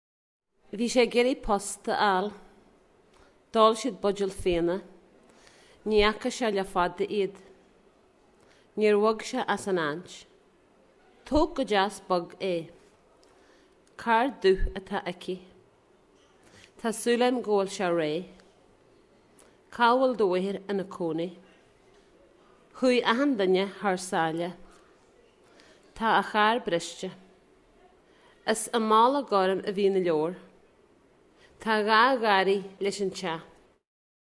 Female speaker, approx. 40 years old, text passage (Teileann) Images of Donegal — South-West Head at Glencolmcille Malin Bay Coast, 1 Malin Bay Coast, 2